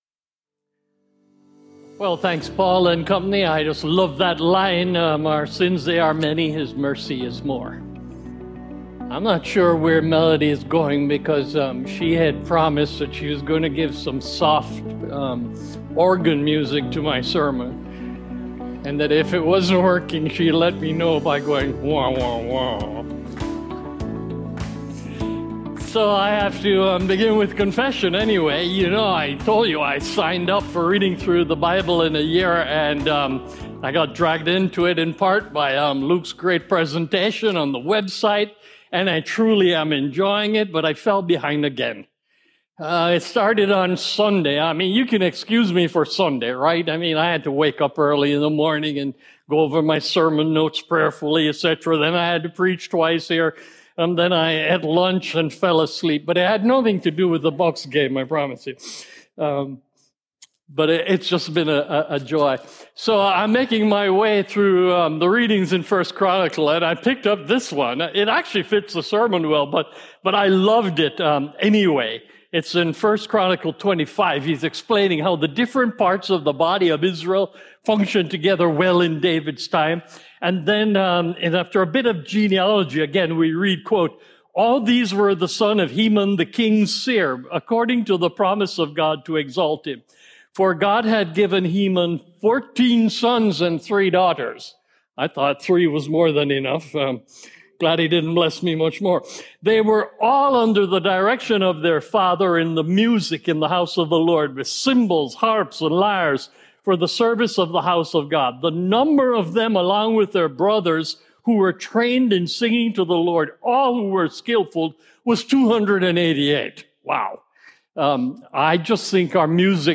Join us in this 13 week sermon series through Joshua and discover what it looks like to find our strength and courage in the Lord!